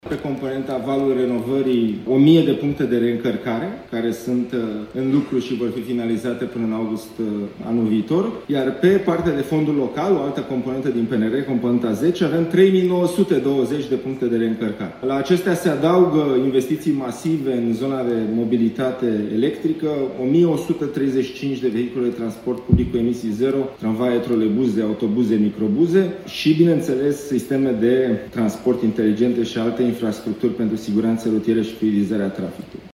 Ministrul Investițiilor și Proiectelor Europene, Dragoș Pâslaru: „Pe componenta Valul renovării, o mie de puncte de reîncărcare sunt în lucru și vor fi finalizate până în august anul viitor”
Precizările au fost făcute la Forumul Mobilității Sustenabile și Accesibile, organizat de APIA (Asociația Producătorilor și Importatorilor de Automobile din România).